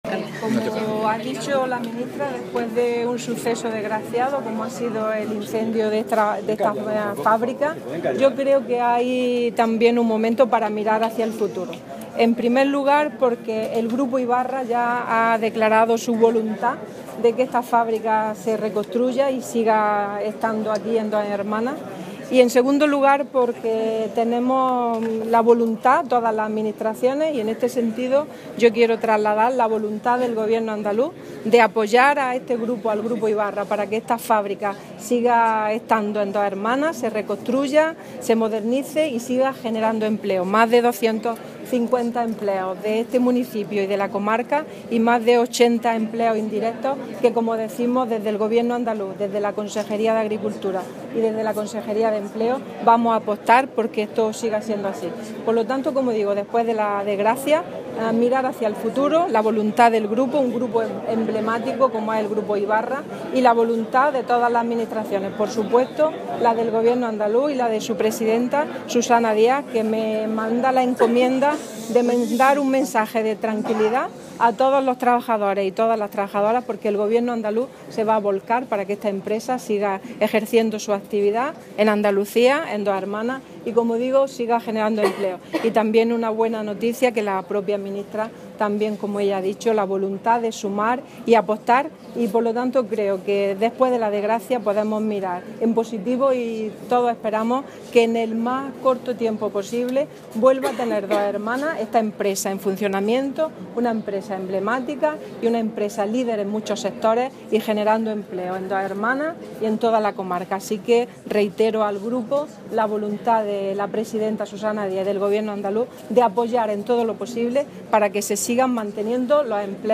Declaraciones Carmen Ortiz sobre incendio en fábrica del Grupo Ybarra en Dos Hermanas (Sevilla)